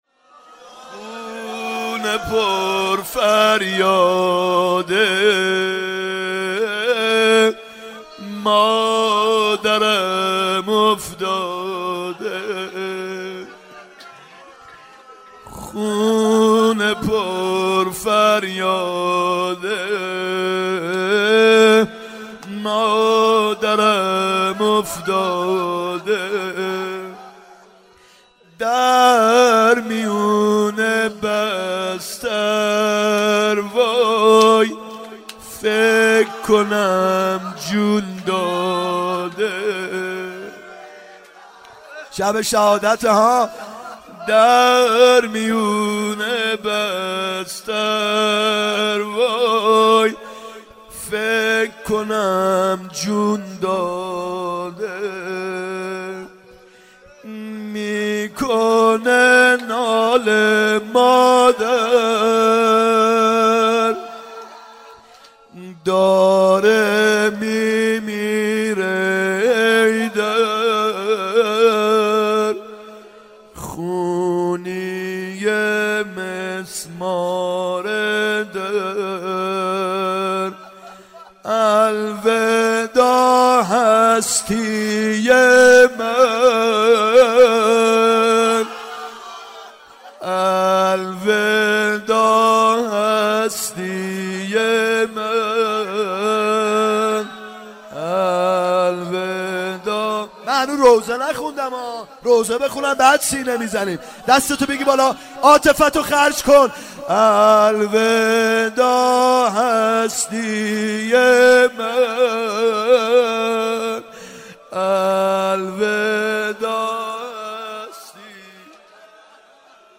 فاطمیه اول شب اول هیئت یامهدی (عج)